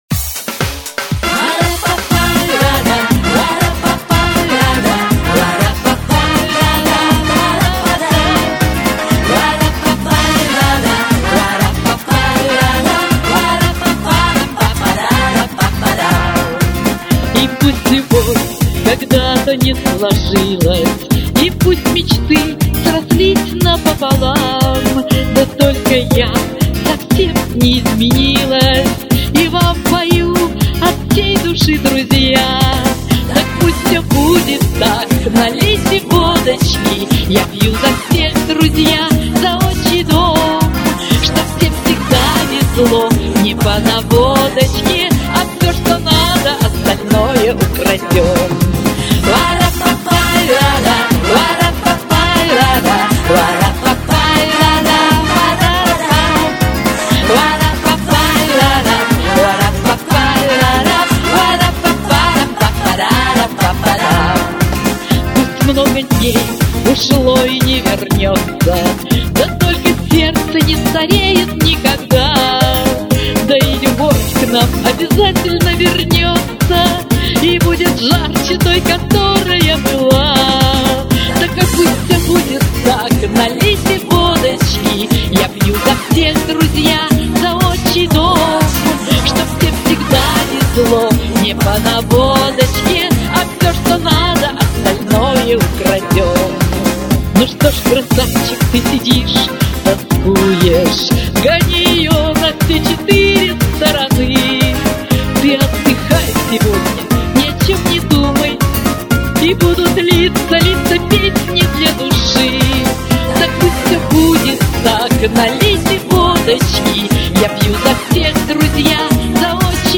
Явно слышно отставание от ноты.
Исполнила с аристократизмом этот шансон!